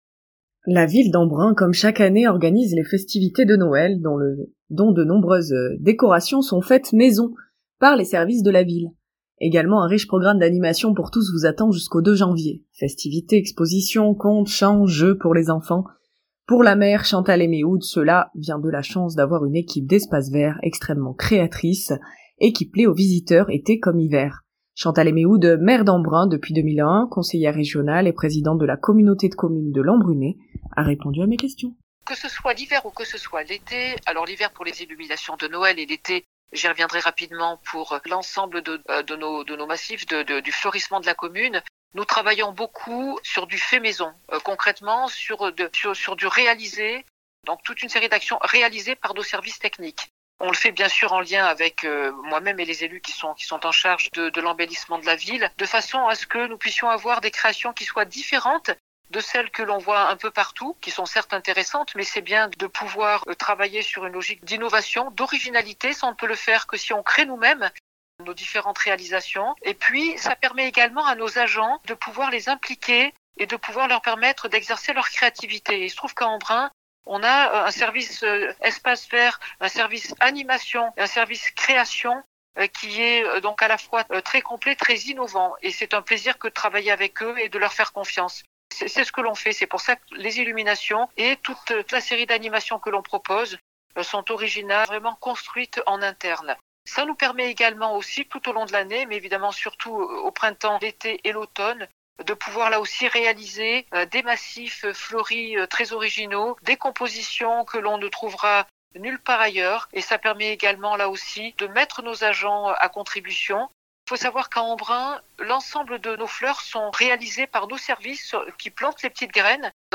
Chantal Eymeoud, Maire d'Embrun depuis 2001, conseillère régionale et présidente de la communauté de communes de l'Embrunais, répond aux questions